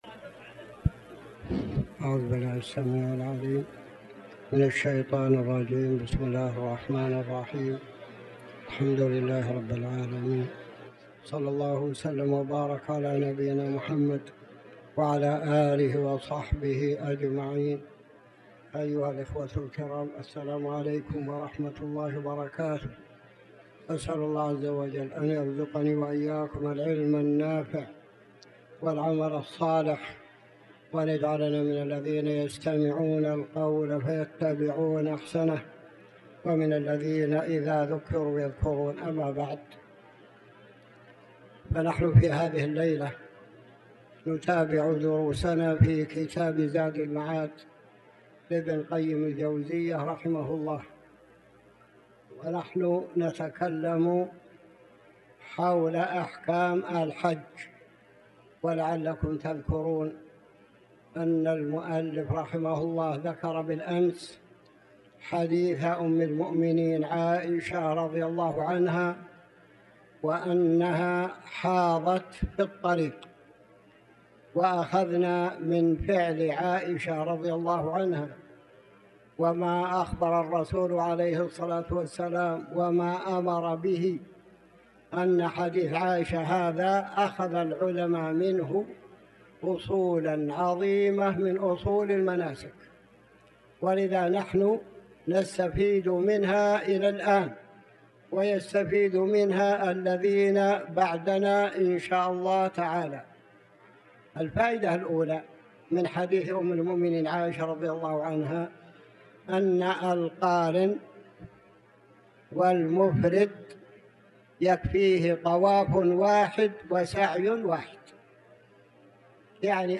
تاريخ النشر ٢ جمادى الأولى ١٤٤٠ هـ المكان: المسجد الحرام الشيخ